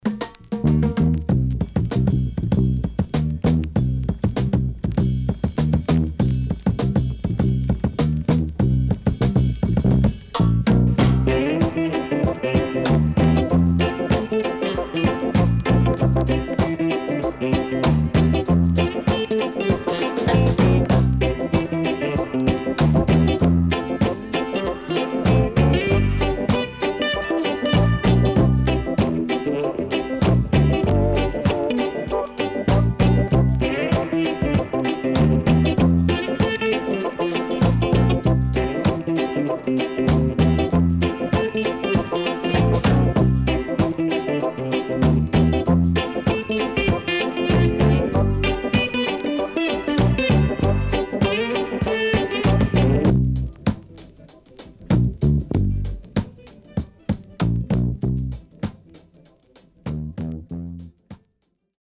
HOME > REGGAE / ROOTS  >  INST 70's
SIDE A:所々チリノイズ入ります。